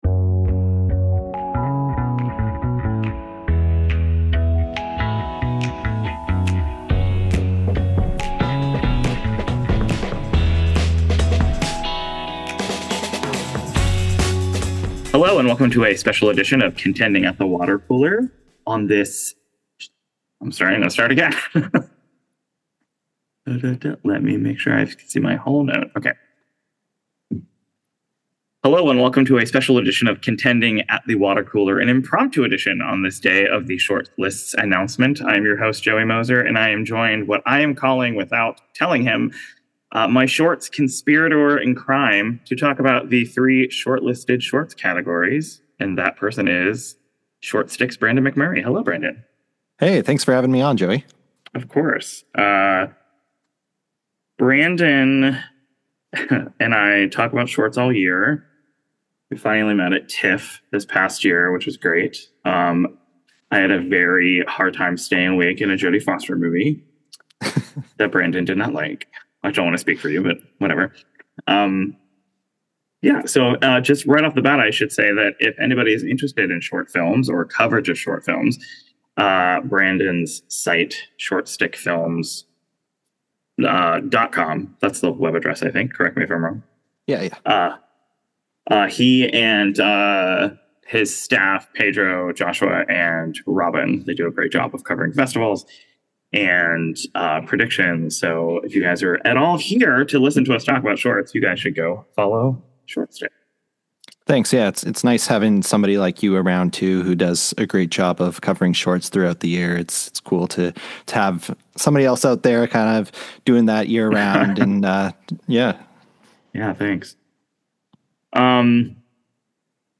We chatted hours after the shortlists dropped, so these reactions are very fresh!